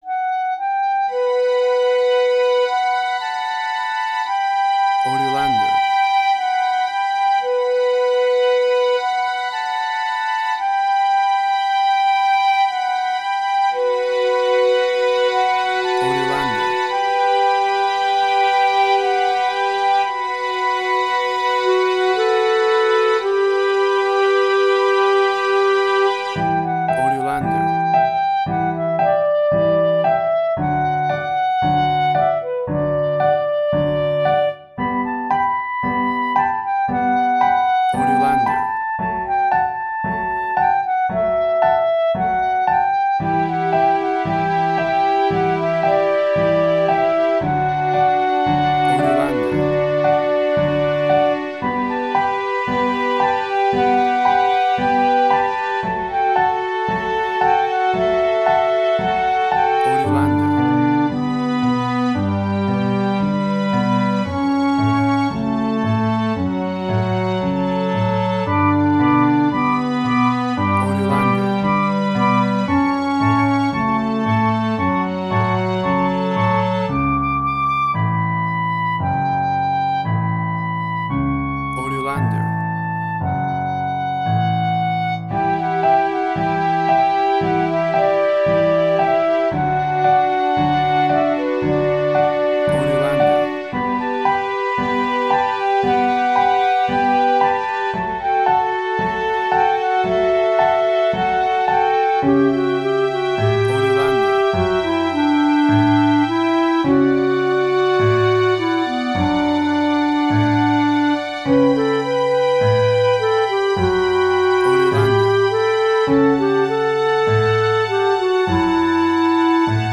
emotional music
WAV Sample Rate: 16-Bit stereo, 44.1 kHz
Tempo (BPM): 57